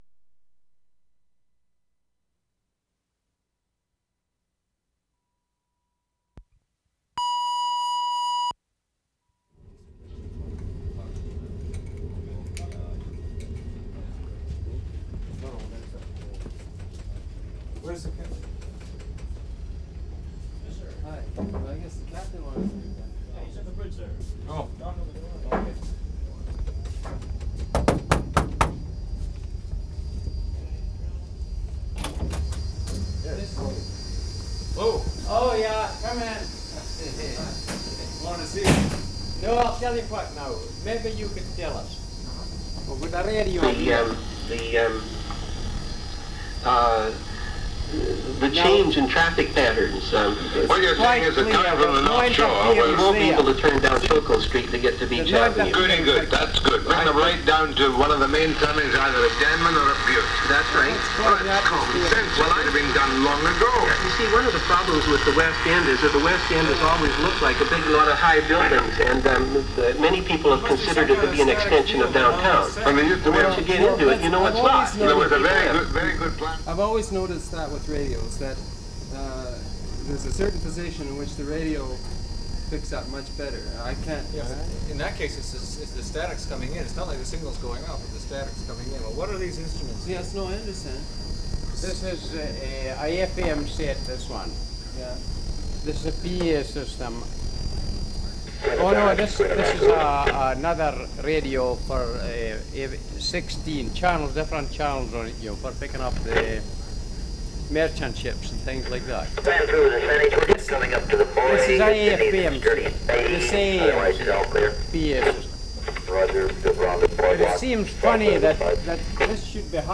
FERRY TRIP TO NANAIMO March 14, 1973
0'20" knocking on door and entering bridge. Loud radio sound with interference. Radio off and conversation about reception.